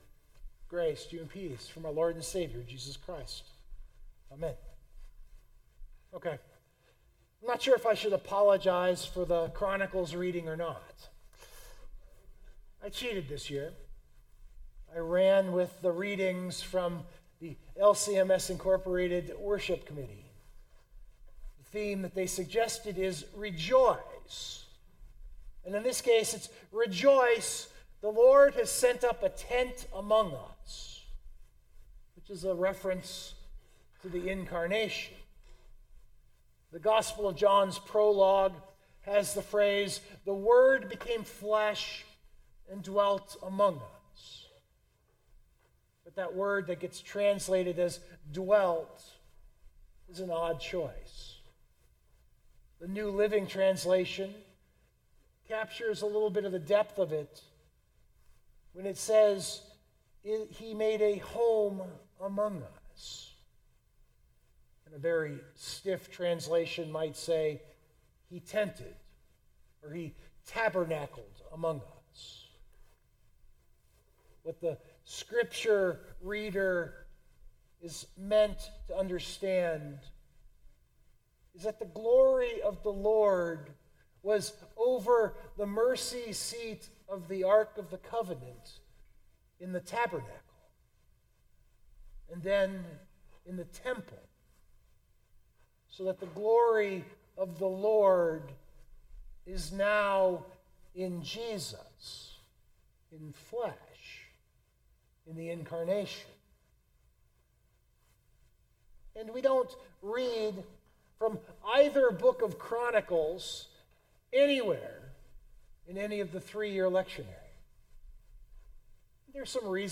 This was the first Midweek Advent service of the season.